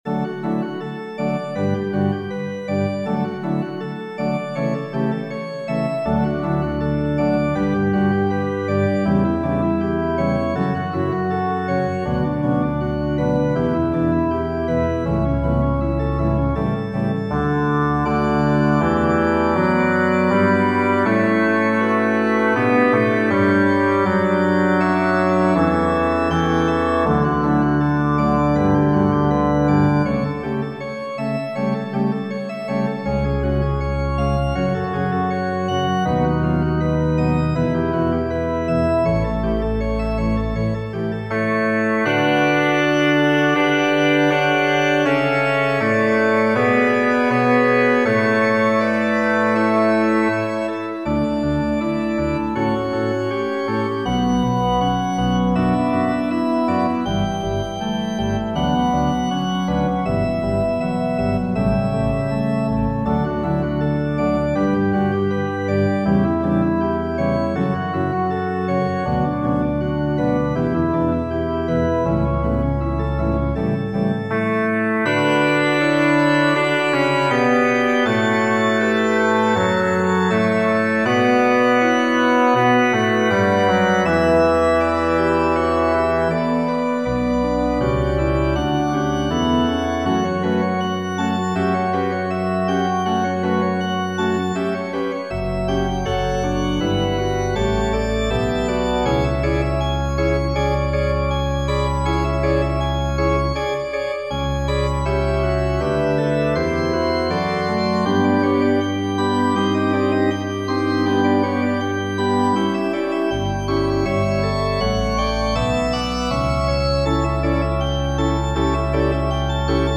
Organ/Organ Accompaniment
Organ recessional/postlude on the hymn tune DAVIS (by Lewis).